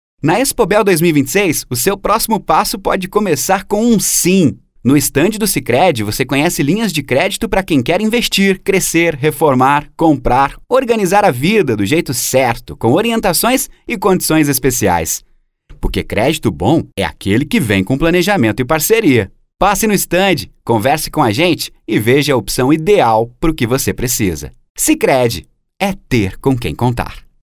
DEMO LIMPO SECO PADRÃO :
Padrão
Animada